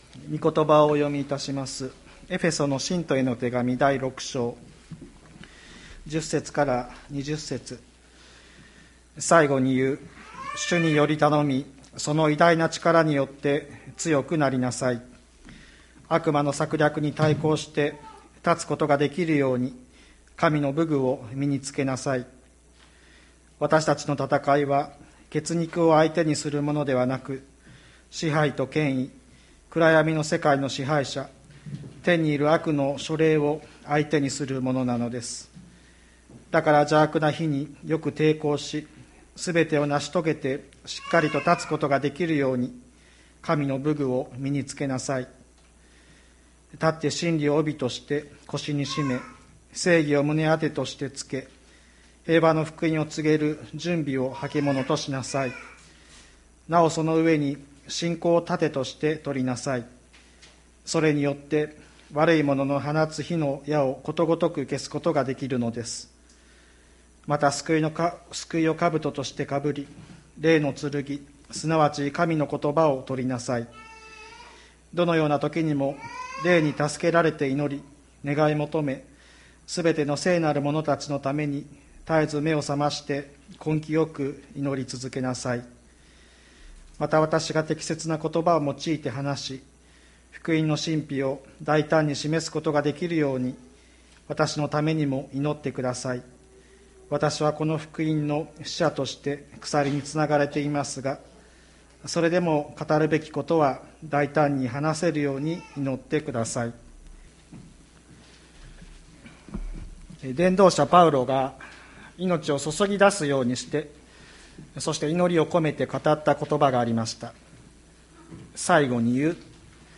2021年02月07日朝の礼拝「わたしたちを強くしてくださる方」吹田市千里山のキリスト教会